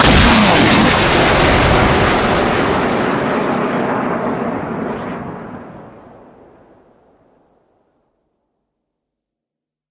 На этой странице представлены звуки работы РСЗО \
Звук выстрела из РСЗО Град